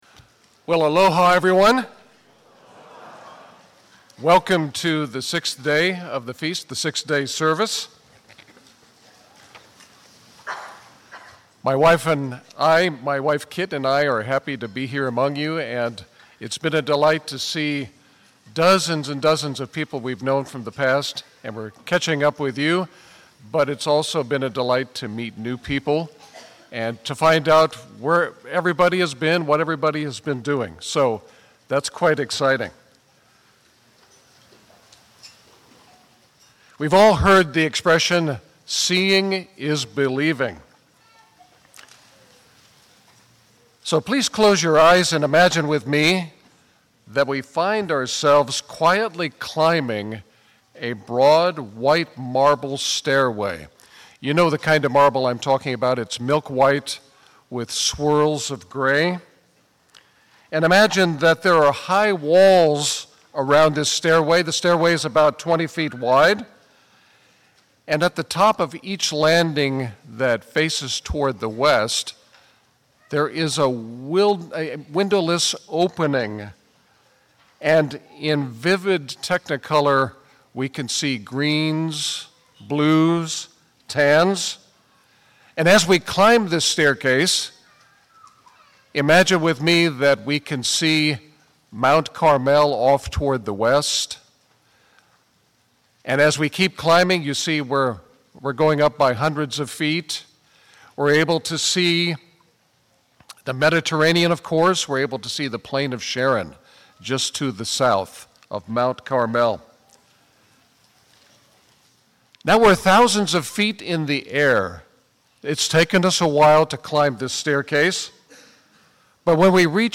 Given in Lihue, Hawaii